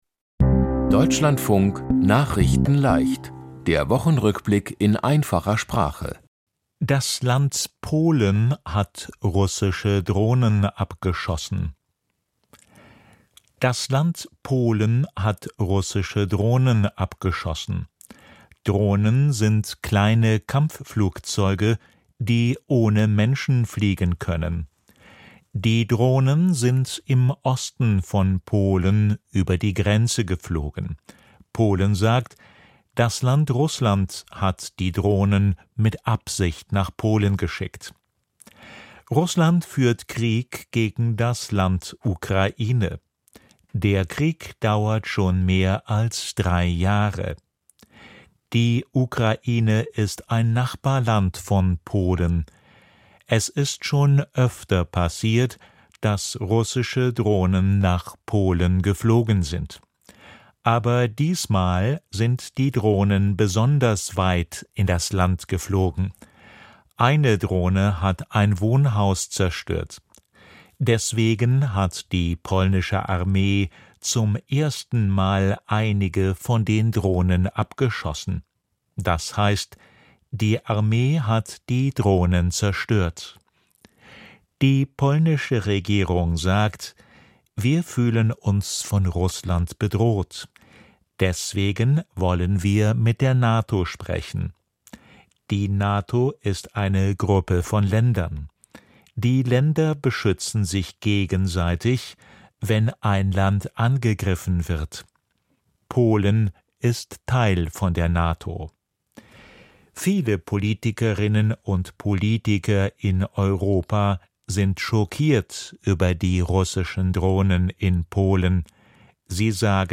Die Themen diese Woche: Das Land Polen hat russische Drohnen abgeschossen, Bekannter Aktivist im Land USA erschossen, In London gibt es ein neues Bild von dem Künstler Banksy, Bei einer Katastrophe brauchen Menschen mit Behinderung besonderen Schutz und Ticket-Verkauf für die Fußball-Weltmeisterschaft hat begonnen. nachrichtenleicht - der Wochenrückblick in einfacher Sprache.